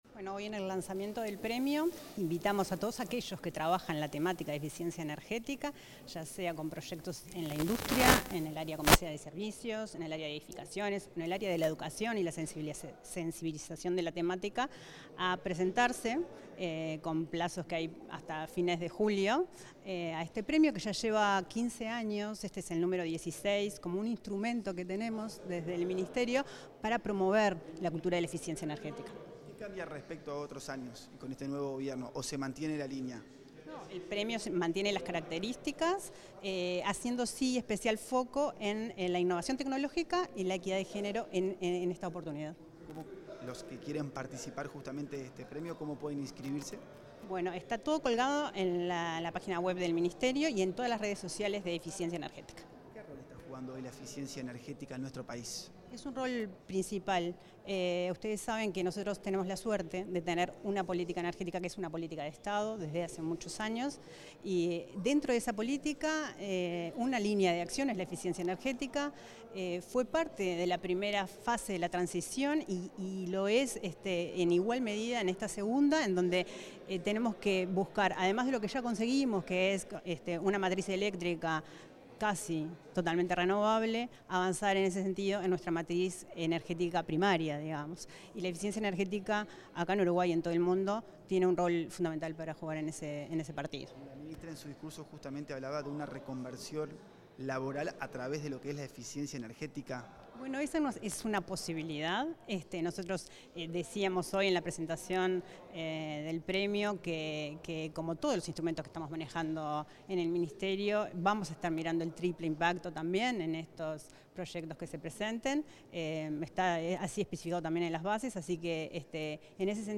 Declaraciones a la prensa de la directora nacional de Energía, Arianna Spinelli
La directora nacional de Energía, Arianna Spinelli, dialogó con la prensa luego del lanzamiento del Premio Nacional de Eficiencia Energética de 2025.